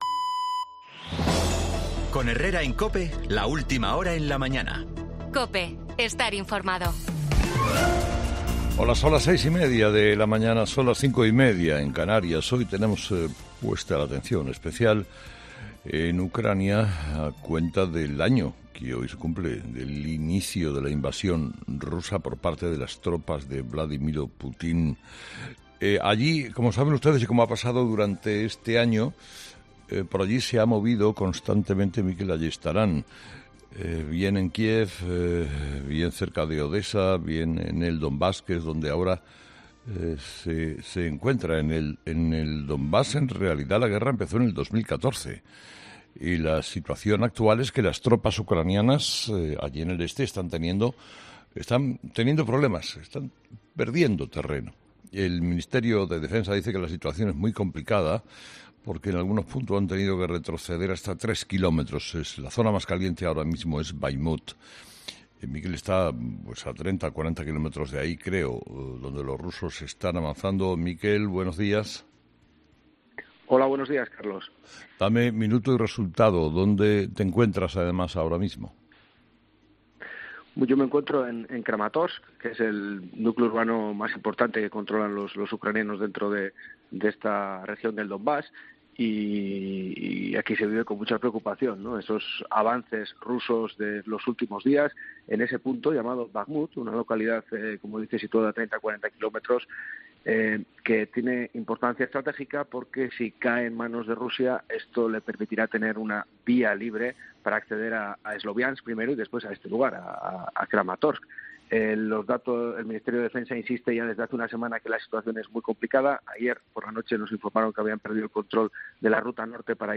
crónica
desde Kiev cuando se cumple un año de la guerra